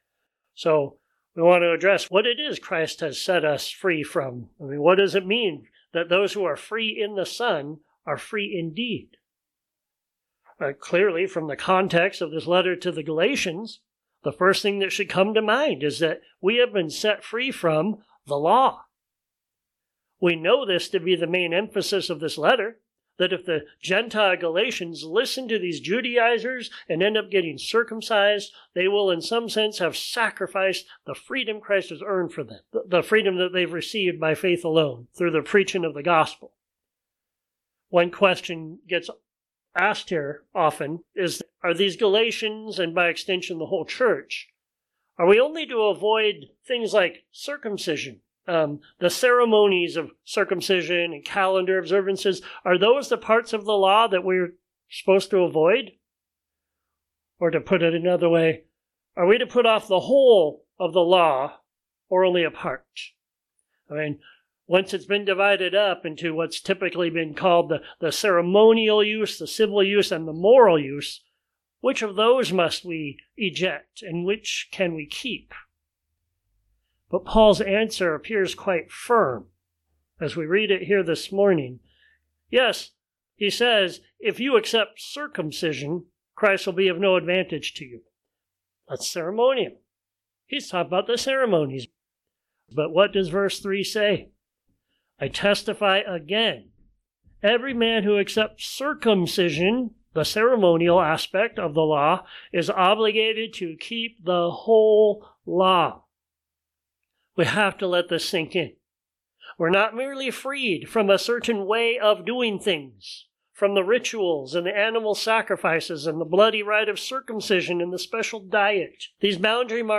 Galatians 5:1-12 Freedom From Sermons Share this: Share on X (Opens in new window) X Share on Facebook (Opens in new window) Facebook Like Loading...